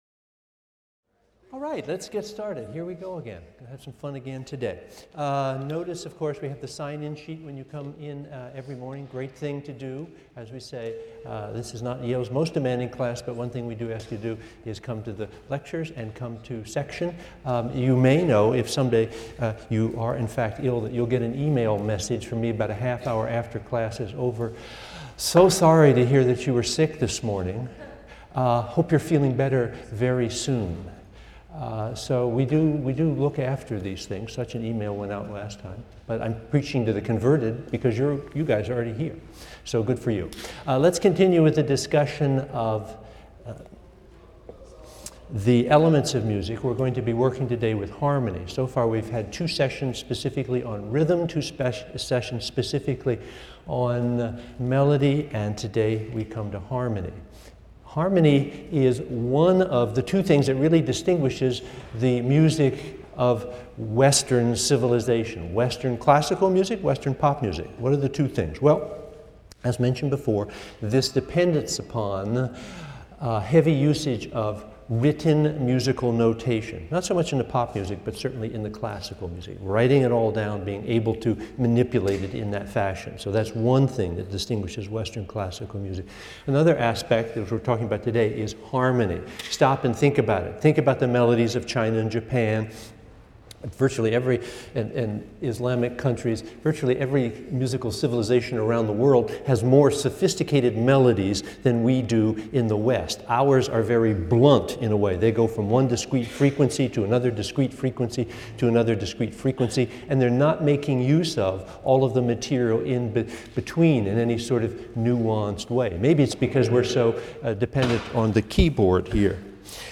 MUSI 112 - Lecture 7 - Harmony: Chords and How to Build Them | Open Yale Courses